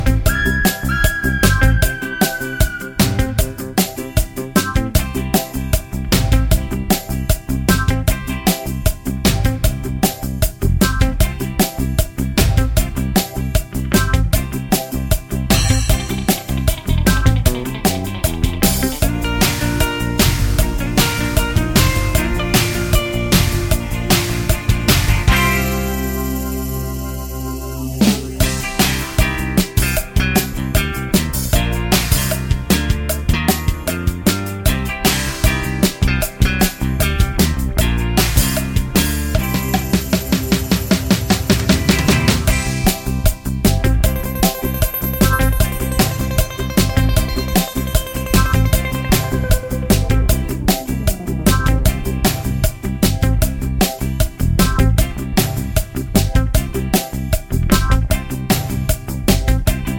no Backing Vocals Punk 2:51 Buy £1.50